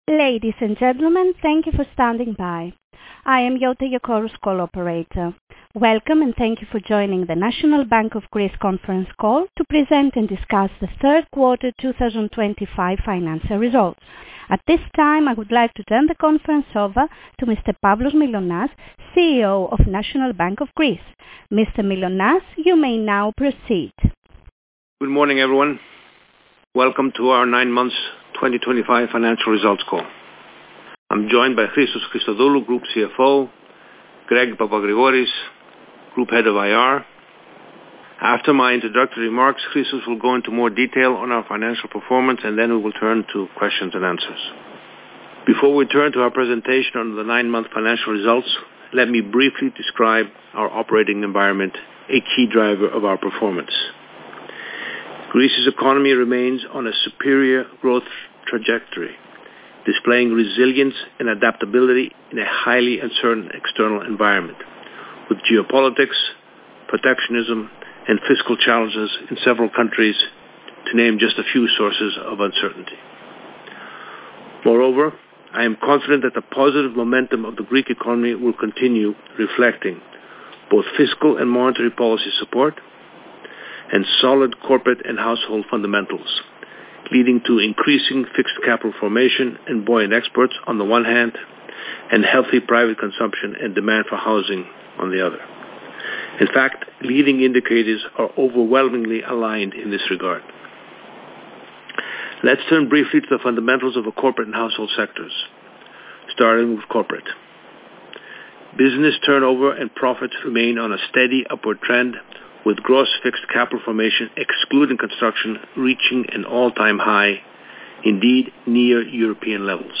Τηλεδιάσκεψη Δ' Τριμήνου 2025 (Διαθέσιμο μόνο στα Αγγλικά)